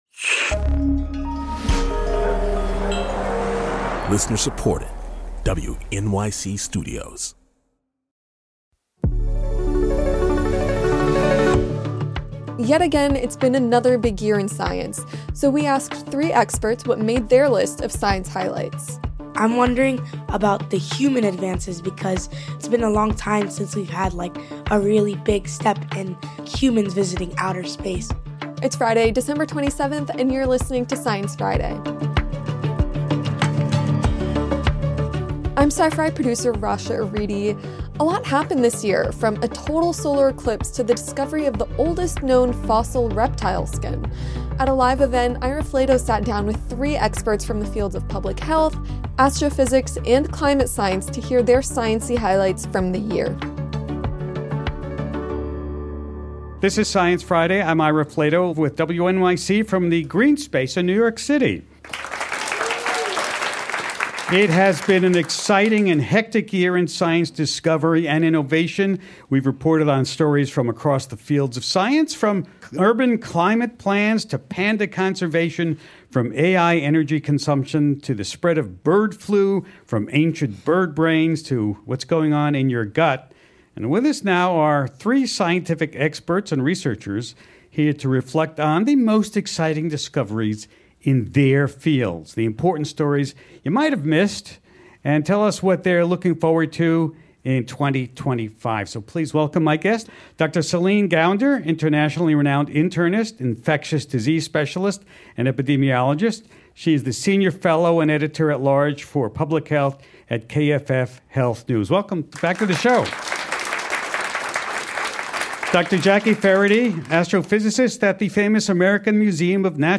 Ira is joined on stage by researchers from three different fields to reflect on all that 2024 brought us in the world of science.